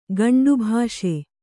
♪ gaṇḍu bhāṣe